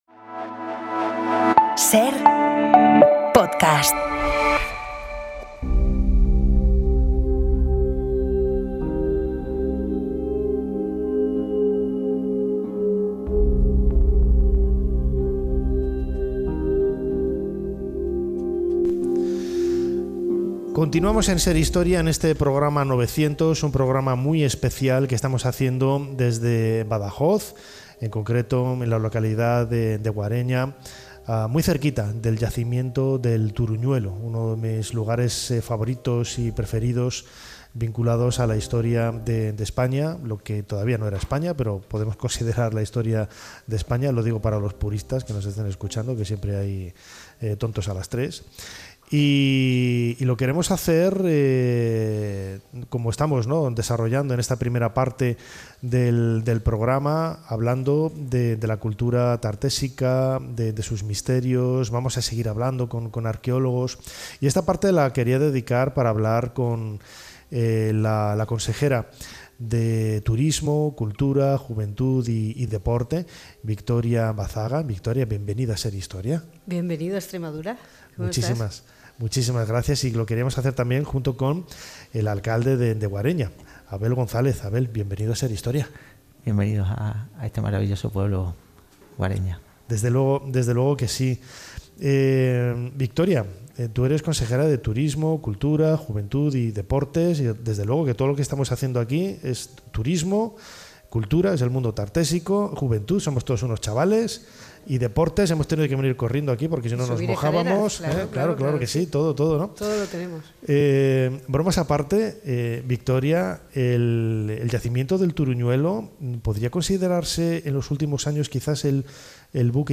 Proyectos arqueológicos y el apoyo de las instituciones 17:00 SER Podcast Nos visita la consejera de cultura de la Junta de Extremadura, Victoria Bazaga, y el alcalde de Guareña, localidad próxima al Turuñuelo, Abel González.